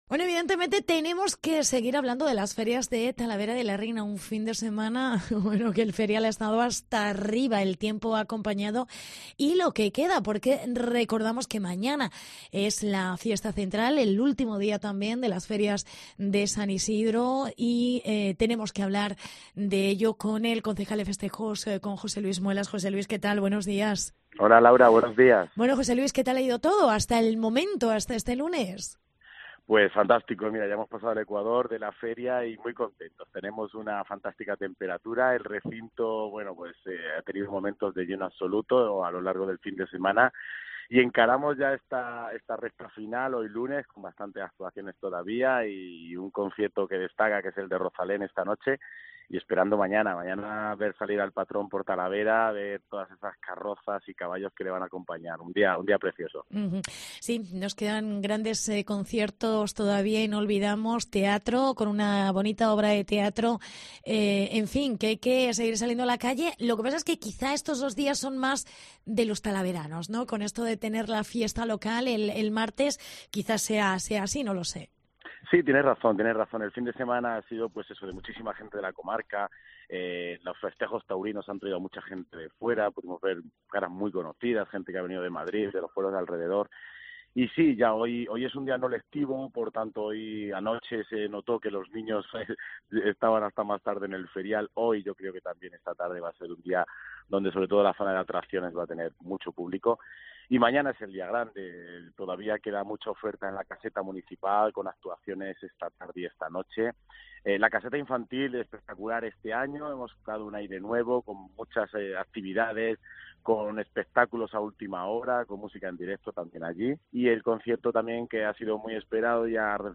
Entrevista con el concejal: José Luis Muelas